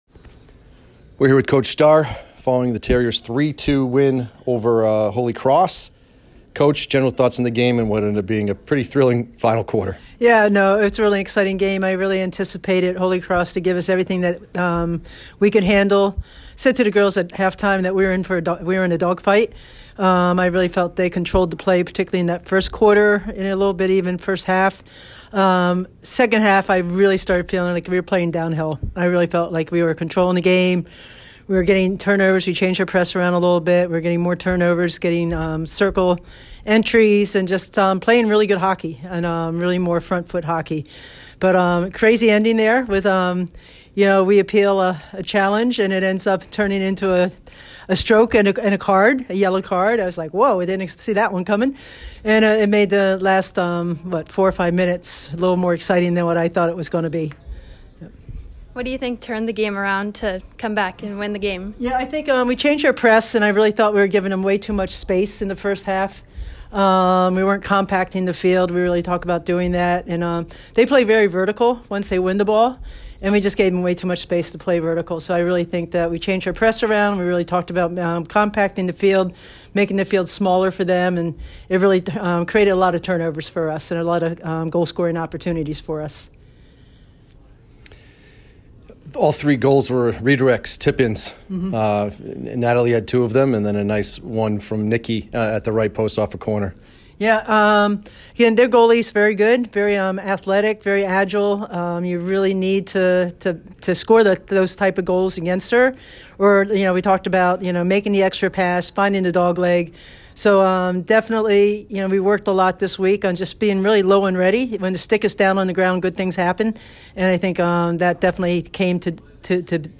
Holy Cross Postgame Interview